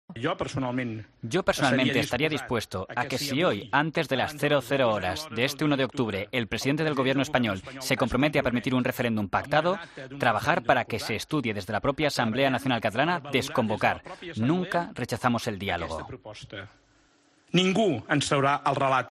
Sánchez,  en rueda de prensa junto con el líder de Òmnium Cultural, Jordi Cuixart, ha criticado lo que ha denominado como "sitio" aplicado por el Gobierno a quienes organizan el referéndum ilegal del 1-O y ha admitido que "con esta presión puede ser difícil una alta participación", pues ya es "una heroicidad haber llegado al 1 de octubre".